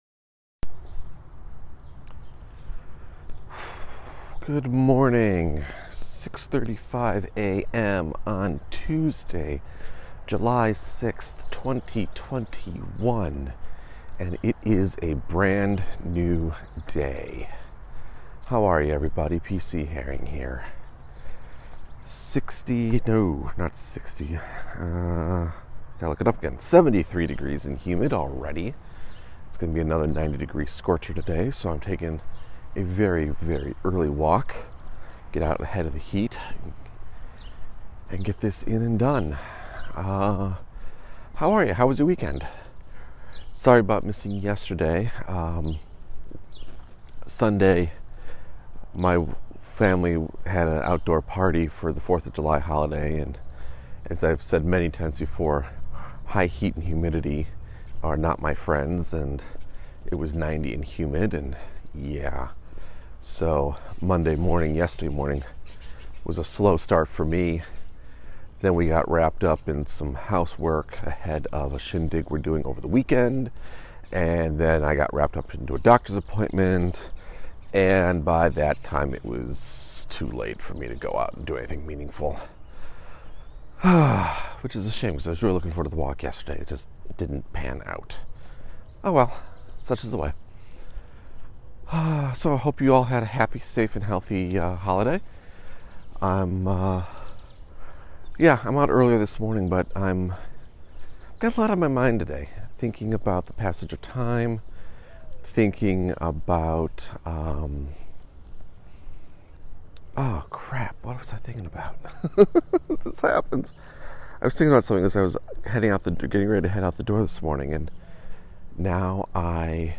Back on the walking path today and today I discuss the frustrating nature of how some creative projects spring to life while others refuse to come together.